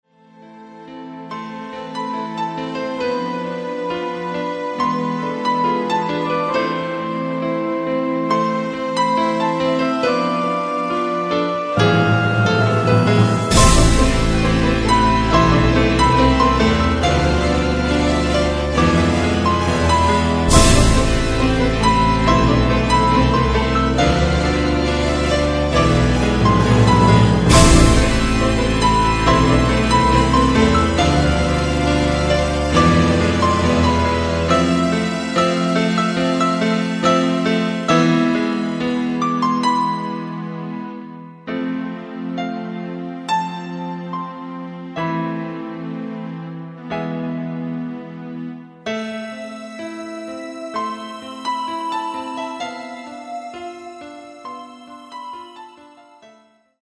Клавиши, перкуссия
фрагмент (334 k) - mono, 48 kbps, 44 kHz